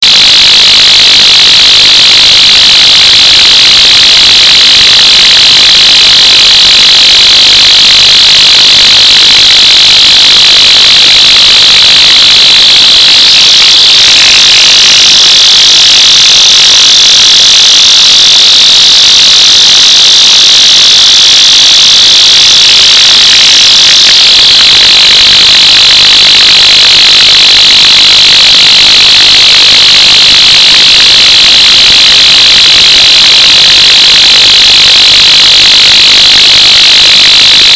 ultrasound speaking into a vibation and recorded with Ultrasound mic
ultrasound-speaking-into-a-vibation-and-recorded-with-Ultrasound-mic.mp3